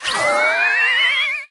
sniper_trail_02.ogg